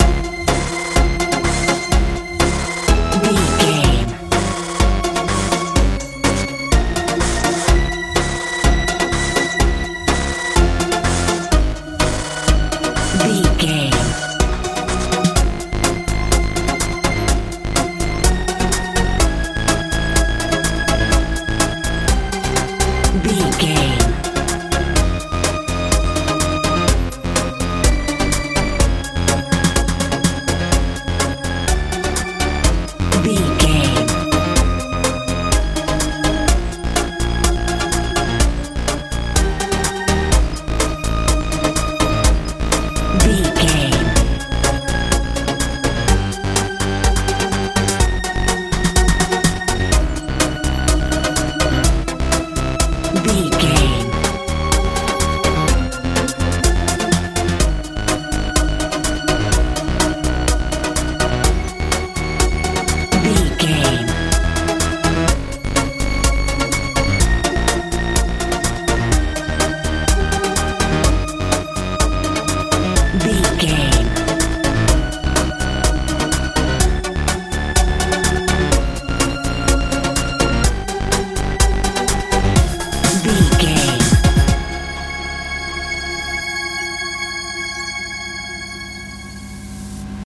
modern dance
Aeolian/Minor
C♯
playful
magical
synthesiser
bass guitar
drums
80s
90s